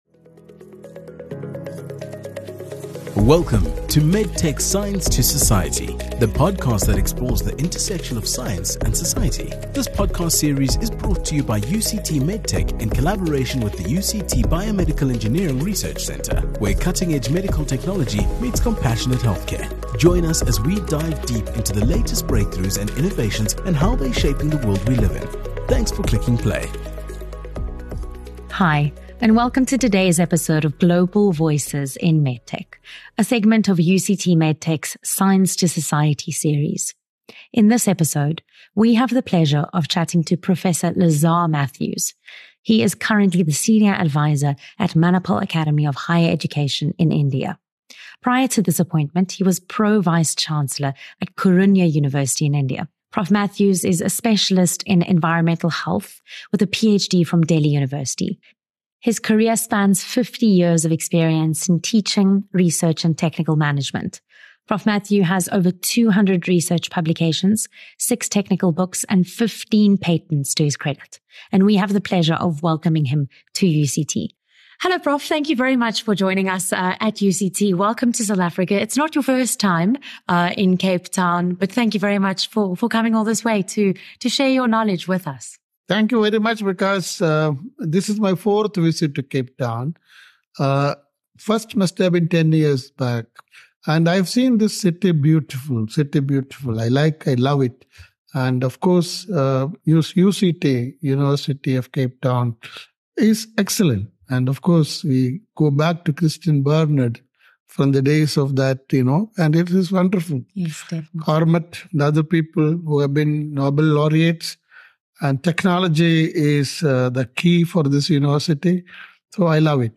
exclusive conversation